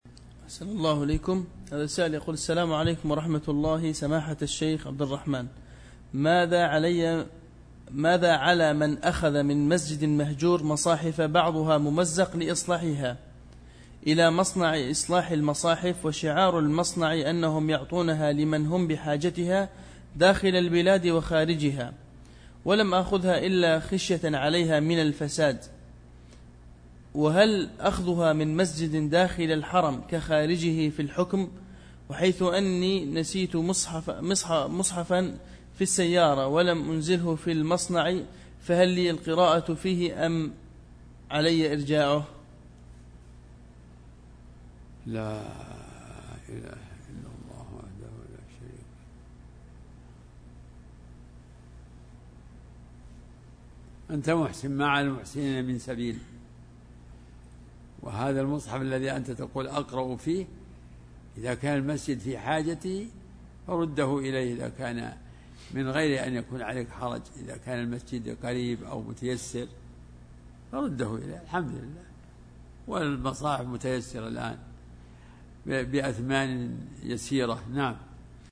فتاوى الدروس